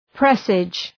{prı’seıdʒ}
presage.mp3